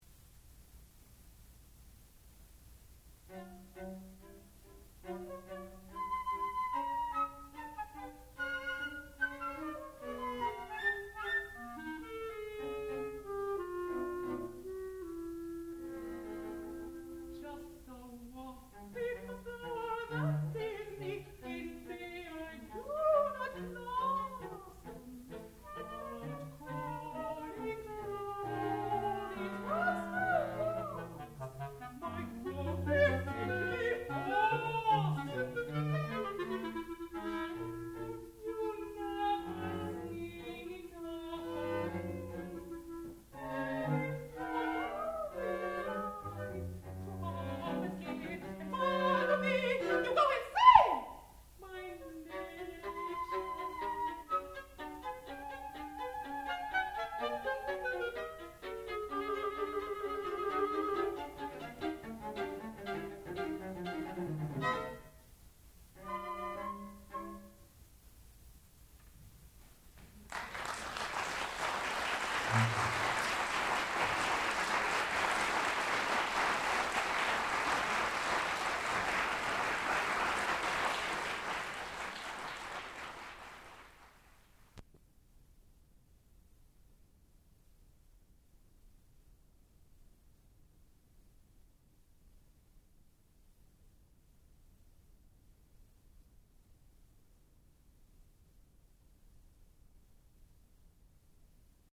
sound recording-musical
classical music
Cambiata Soloists (performer).